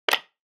Download User Interface login sound effect for free.
User Interface Login